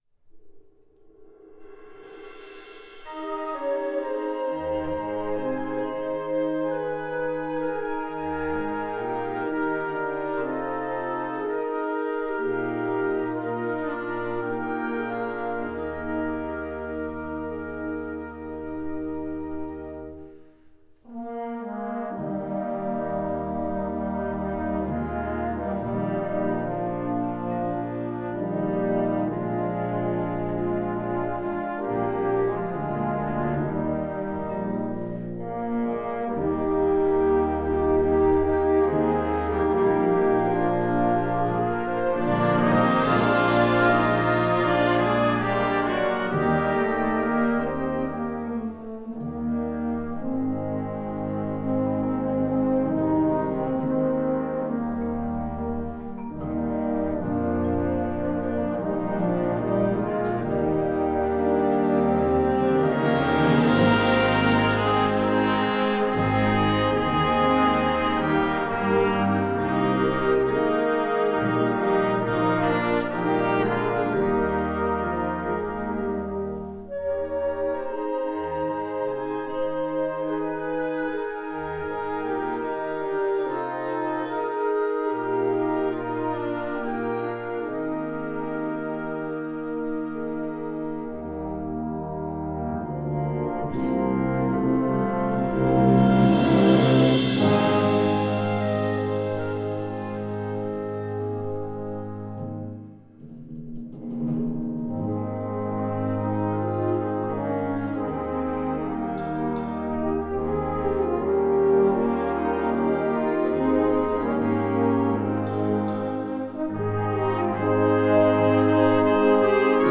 Gattung: Konzertwerk
Besetzung: Blasorchester
Diese schöne walisische Ballade